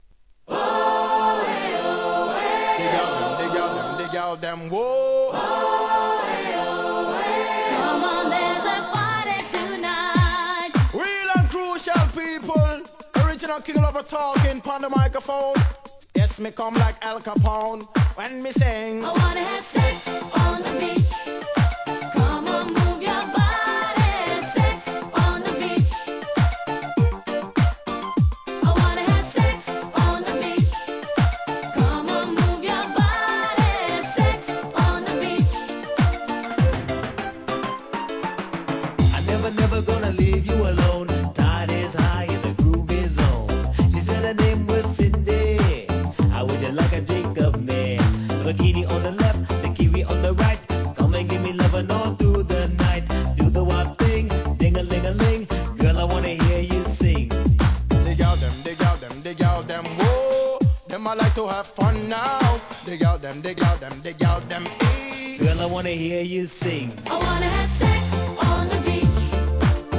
HIP HOP/R&B
REGGAEの香りを取り入れた'90sの誰もが知ってる大ヒット!!!